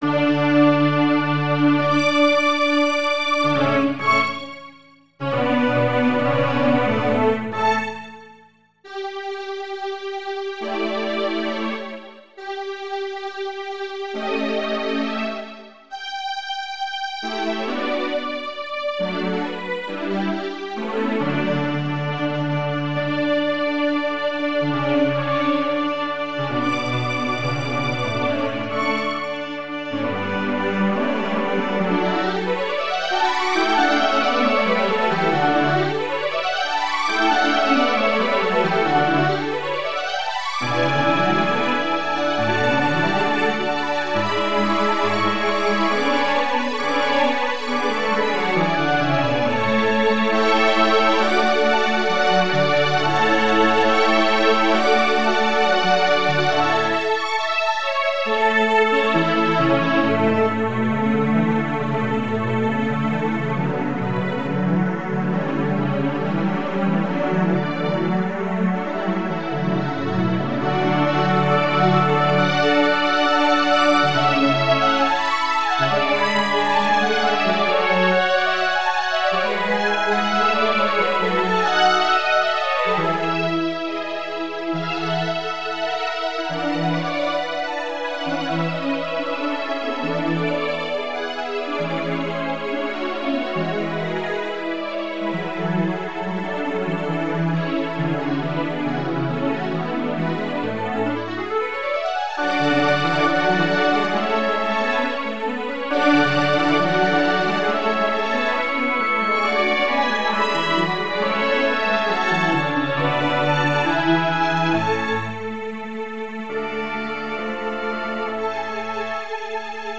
入会を記念して、K385MIDImpeg4を作成しました。